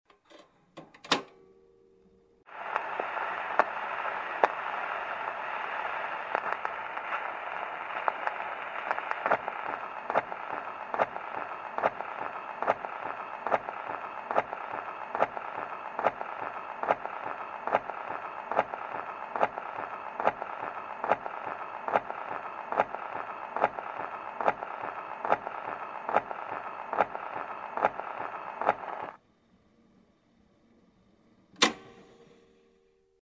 На этой странице собрана коллекция аудиозаписей, воссоздающих характерное звучание старого граммофона: потрескивание виниловой пластинки, скрип запускаемой иглы и тот самый теплый, аналоговый тембр.
Включение старого граммофона звук иглы по пластинке